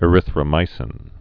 (ĭ-rĭthrə-mīsĭn)